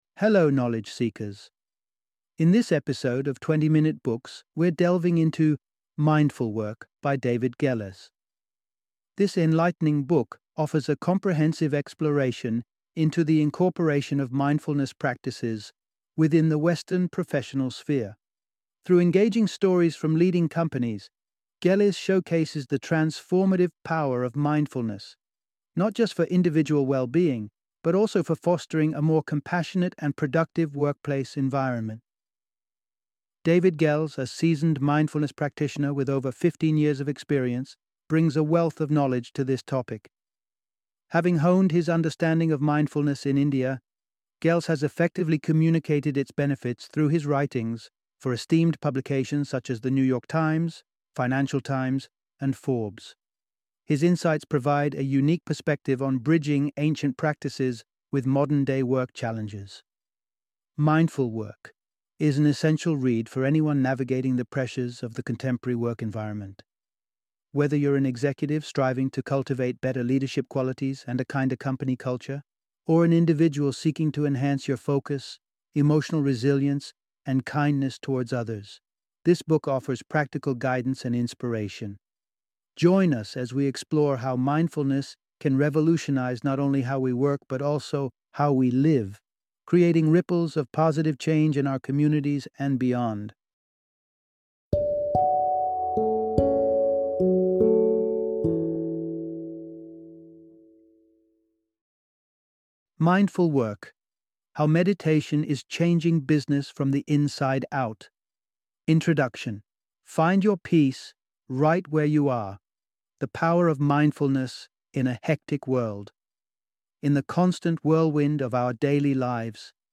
Mindful Work - Book Summary